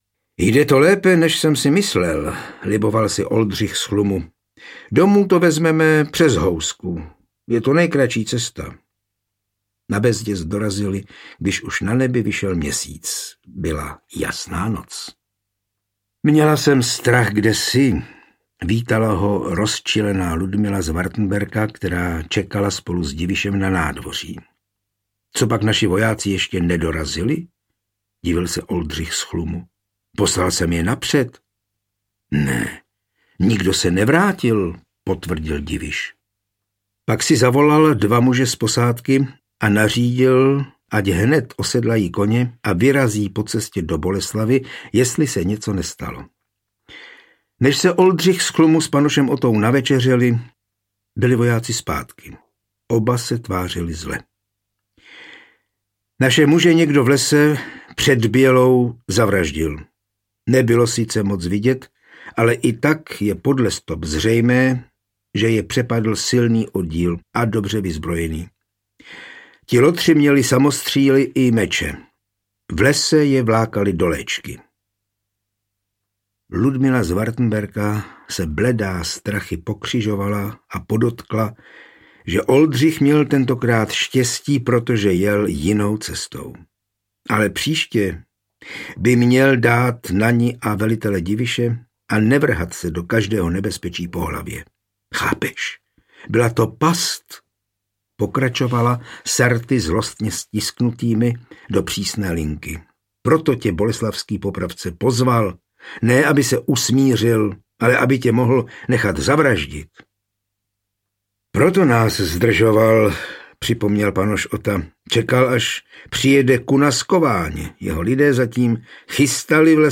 Ukázka z knihy
Bez hudebních předělů a podkresů.